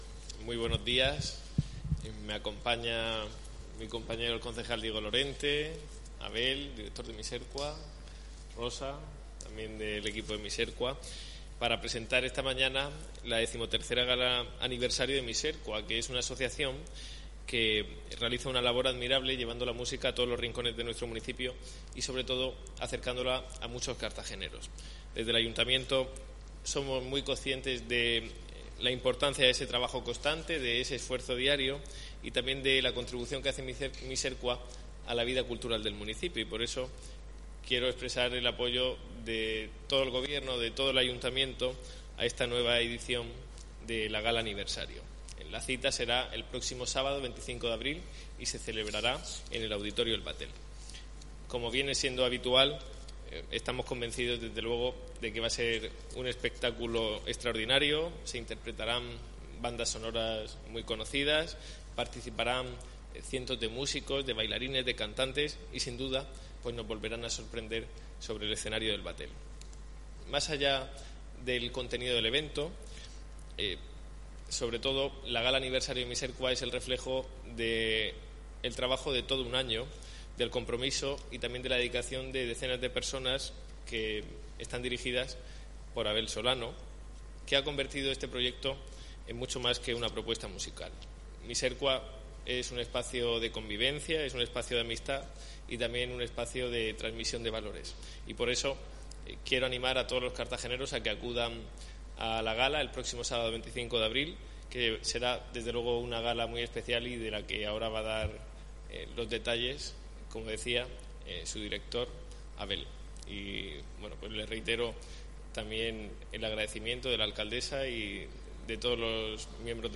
La presentación, a la que también ha asistido el concejal delegado de nuevas tecnologías, Diego Lorente, ha contado con la actuación de dos de los artistas participantes de la gala, quienes han interpretado a piano y voz My Heart Will Go On, el emblemático tema de la banda sonora de Titanic.